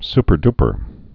(spər-dpər)